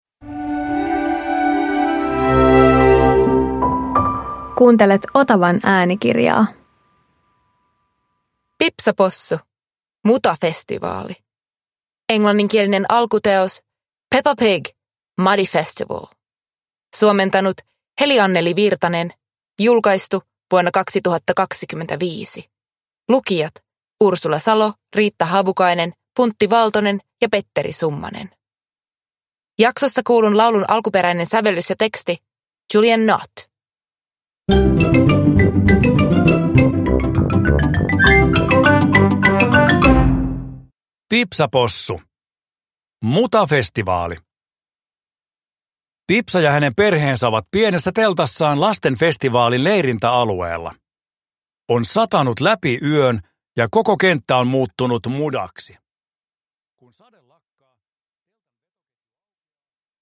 Pipsa Possu - Mutafestivaali – Ljudbok